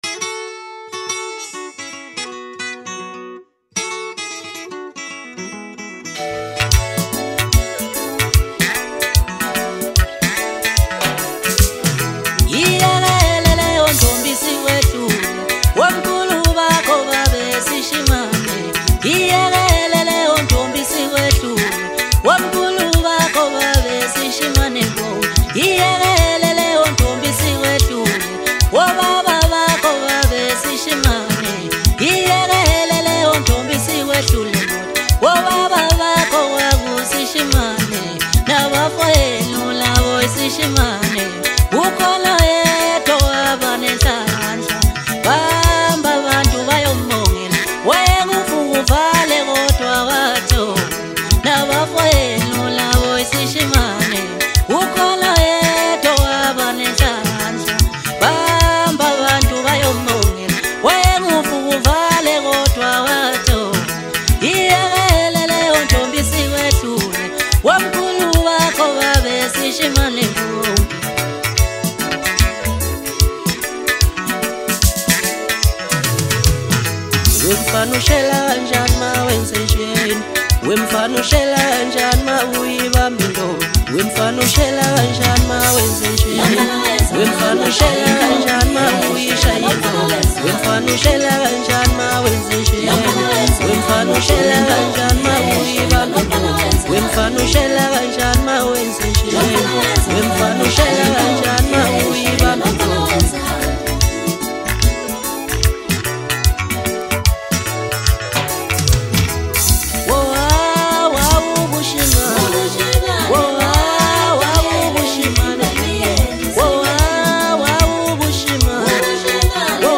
Home » DJ Mix » Hip Hop » Maskandi
South African singer